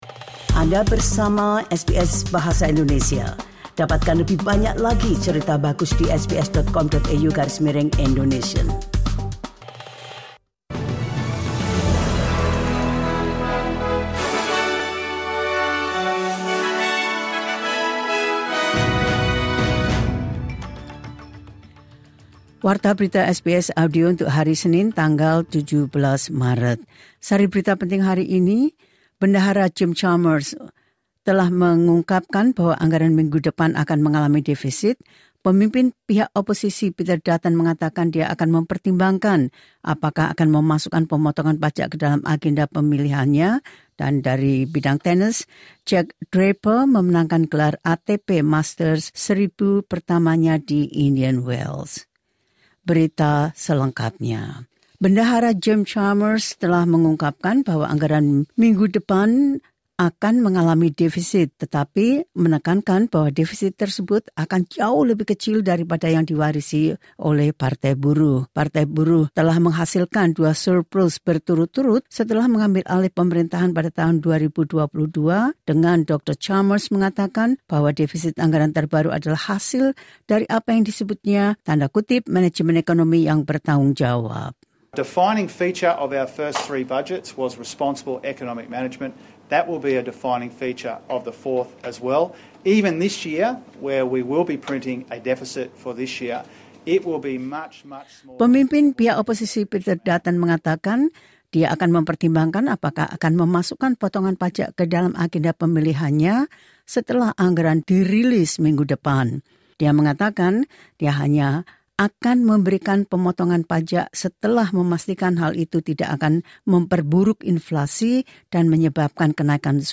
Latest News SBS Audio Indonesian Program – 17 Mar 2025.